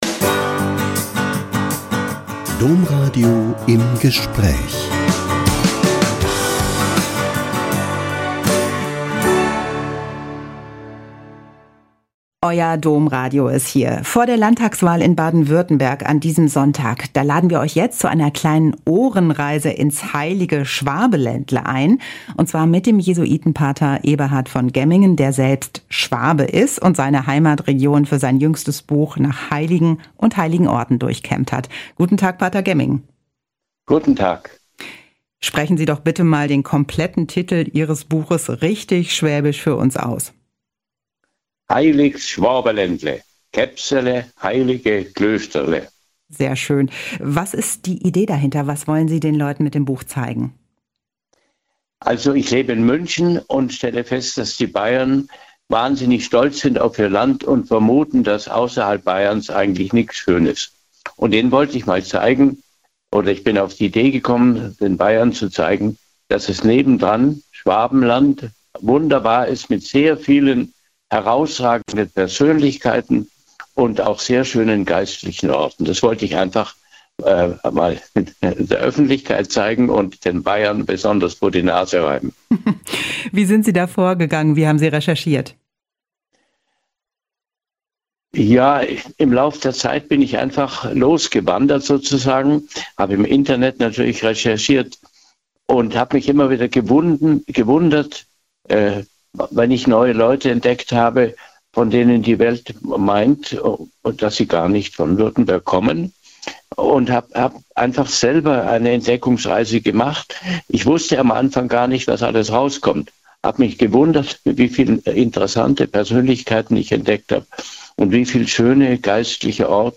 im Interview.